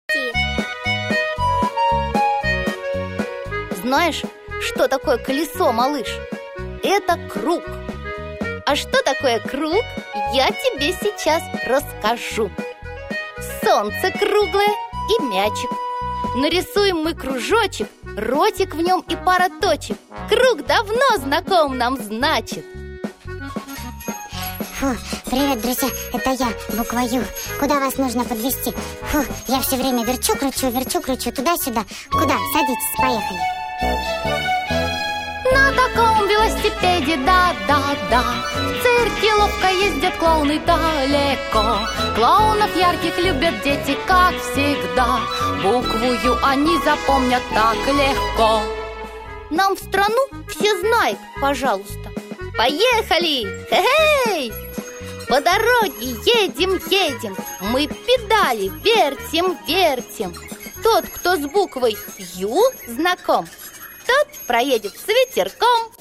Аудиосказки: 29 - Буква Ю